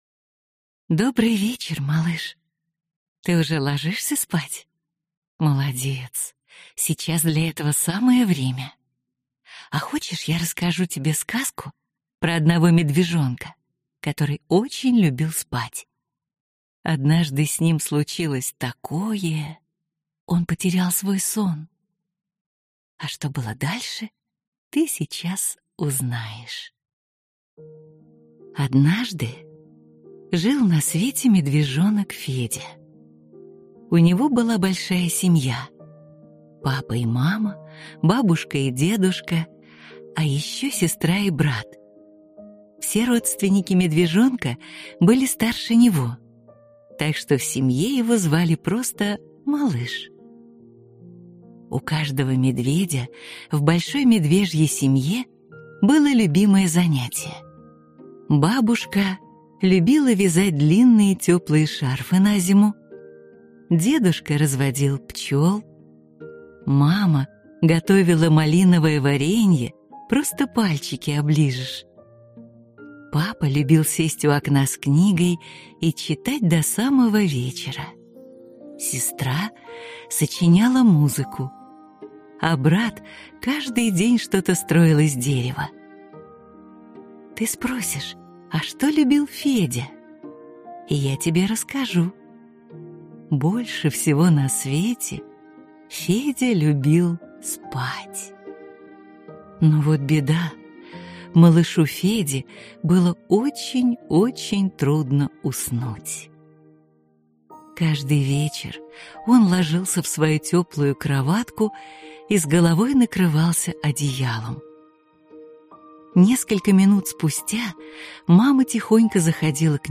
Аудиокнига Медвежонок Федя, который очень хотел уснуть | Библиотека аудиокниг